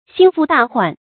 心腹大患 注音： ㄒㄧㄣ ㄈㄨˋ ㄉㄚˋ ㄏㄨㄢˋ 讀音讀法： 意思解釋： 見「心腹重患」。